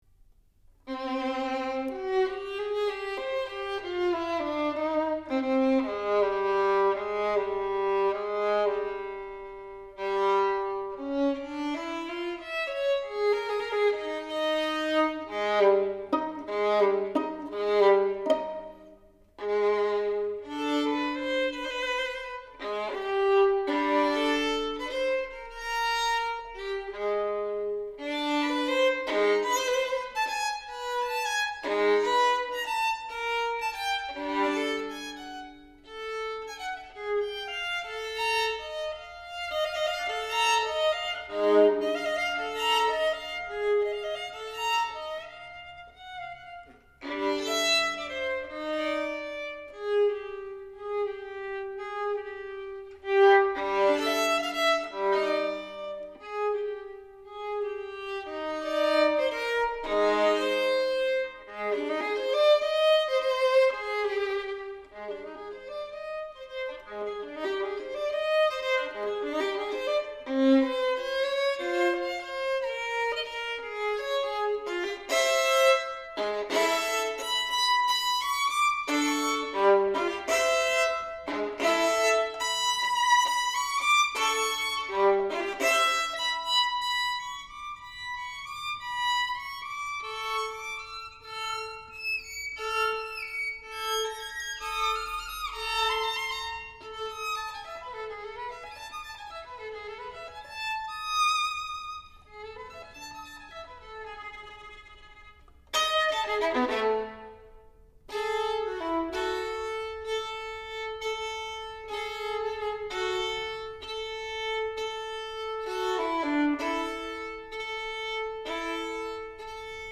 Session Outtakes (unedited)